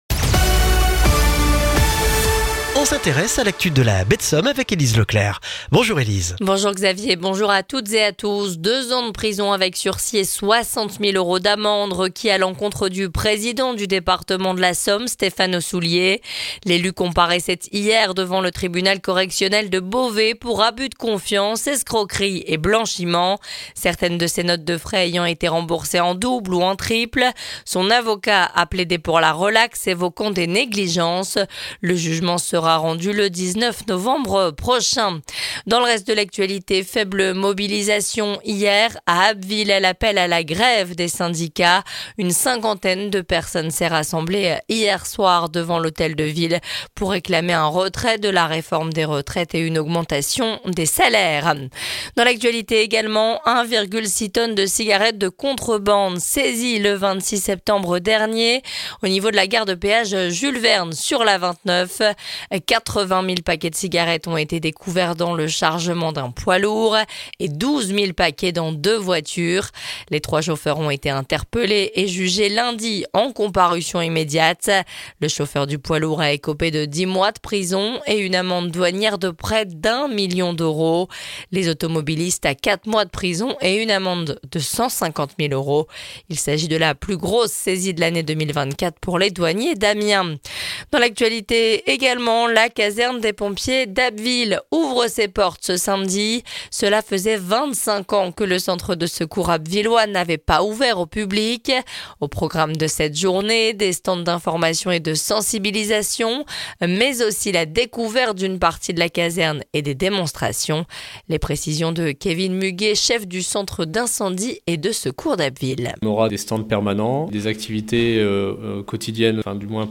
Le journal du mercredi 2 octobre en Baie de Somme et dans la région d'Abbeville